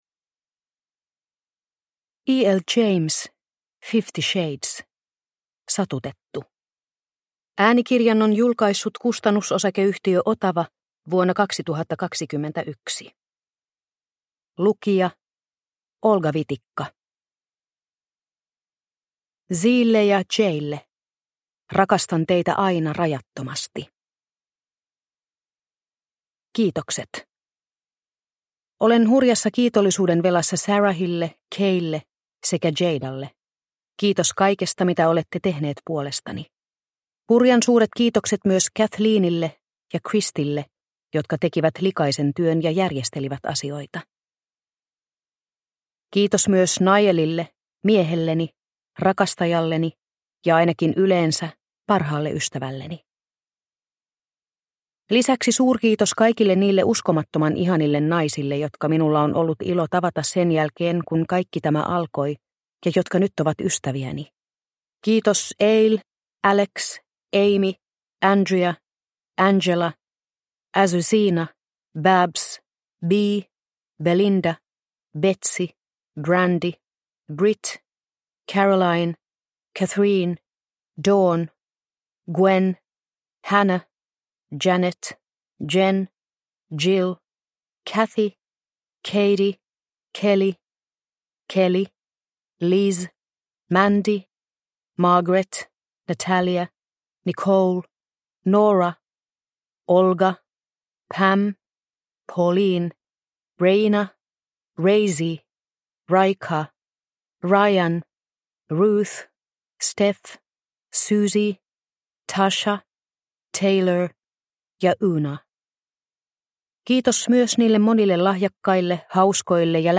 Fifty Shades - Satutettu – Ljudbok – Laddas ner